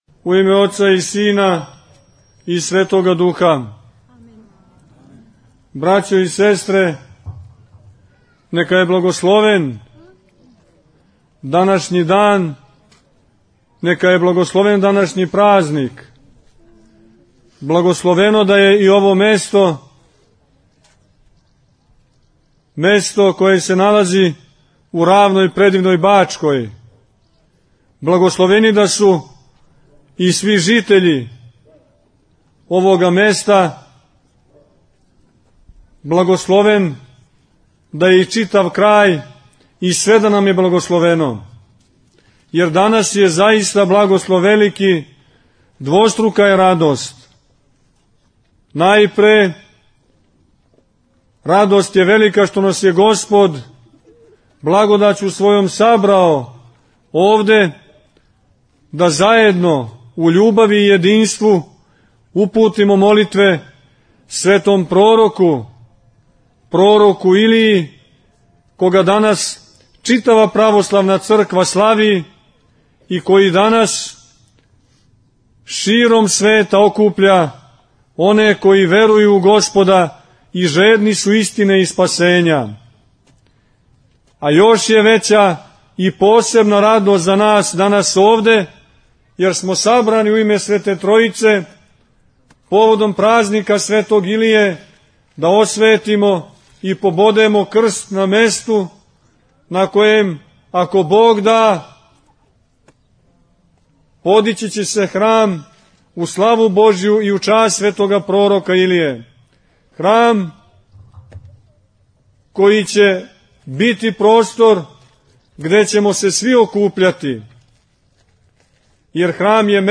Колут - На празник Светога Прoрока Илије, у селу Колуту код Сомбора, Његово Преосвештенство Епископ јегарски Господин др Порфирије освештао је земљиште на којем ће се градити храм Светог Пророка Илије. После чина освећења, Епископ Порфирије је пререзао славски колач и одржао пригодну беседу.